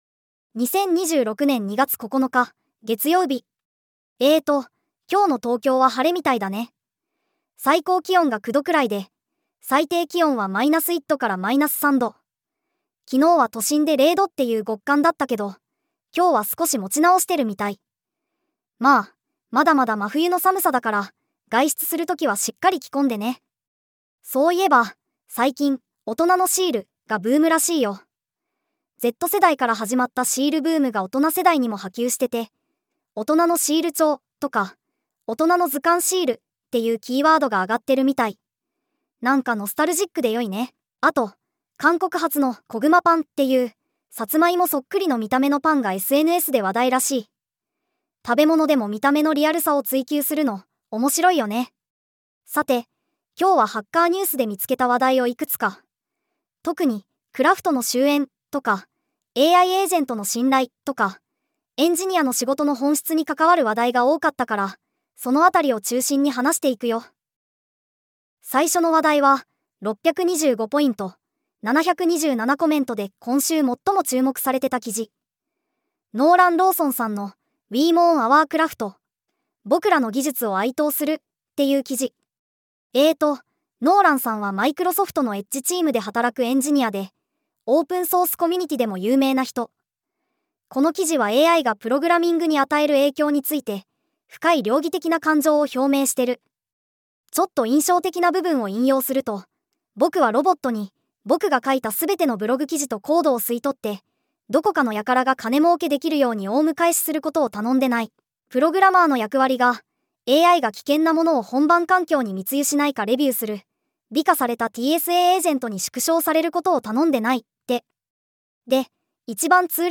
テトさんに技術系ポッドキャストを読んでもらうだけ